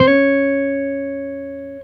Guitar Slid Octave 14-C#3.wav